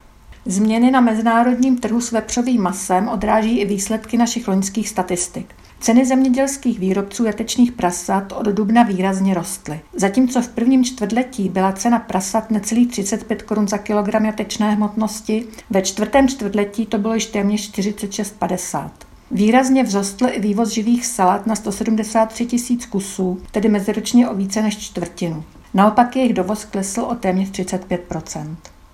Tiskové sdělení: Výroba masa v závěru roku meziročně poklesla Vyjádření